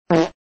Dry Fart Sound Effect 🎵 Sound Effects Free Download